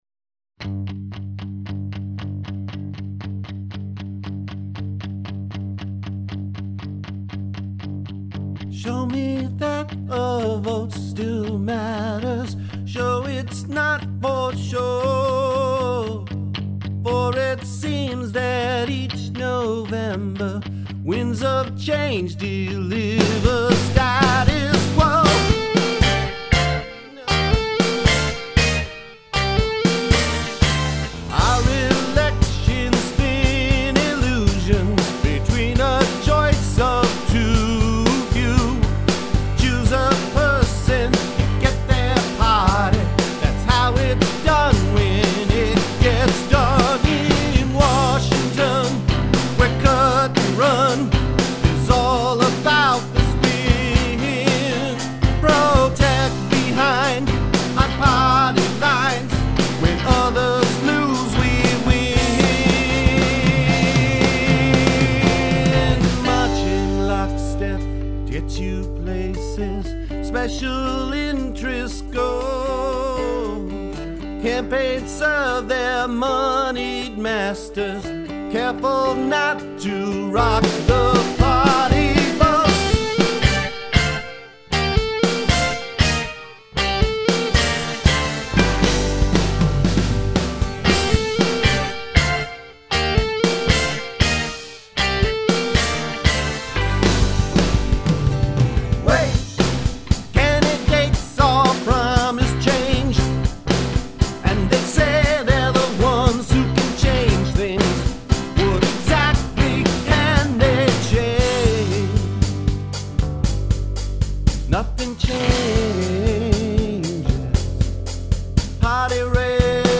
Brand new vocals ... in which I employed a doubling effect for phrasing emphasis.